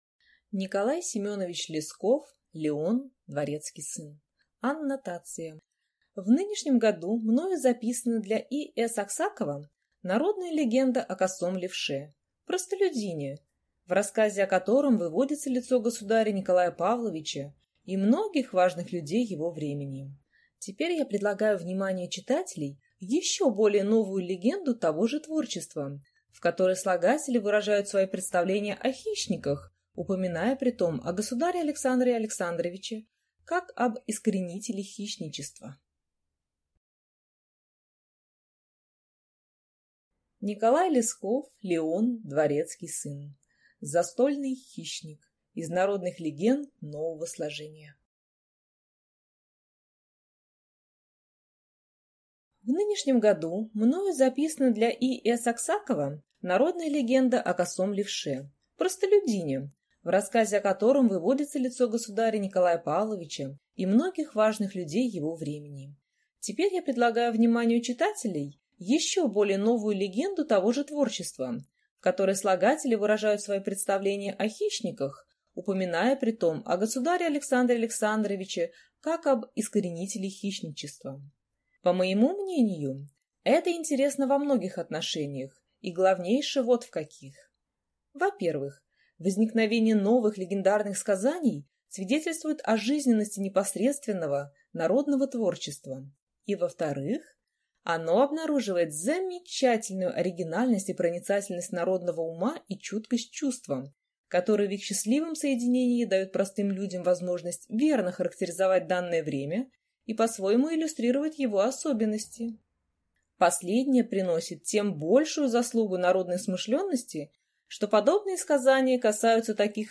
Аудиокнига Леон дворецкий сын | Библиотека аудиокниг